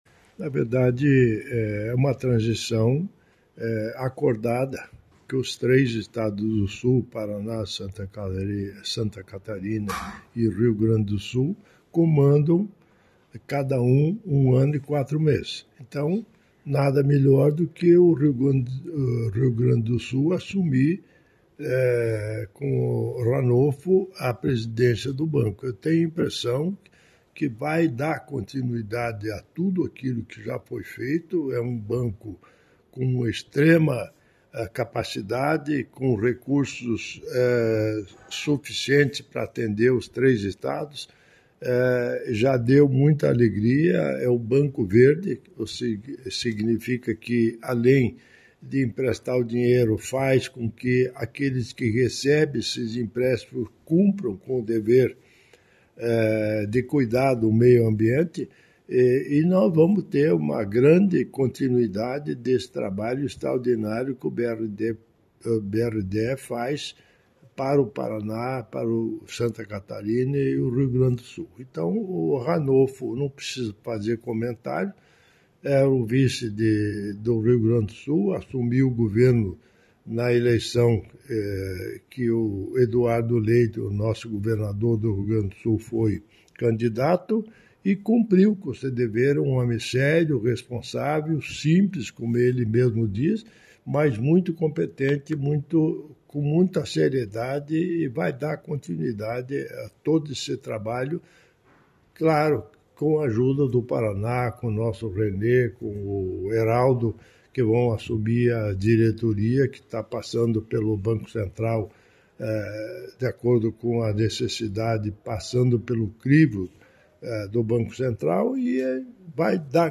Sonora do governador em exercício Darci Piana sobre a posse do novo presidente do BRDE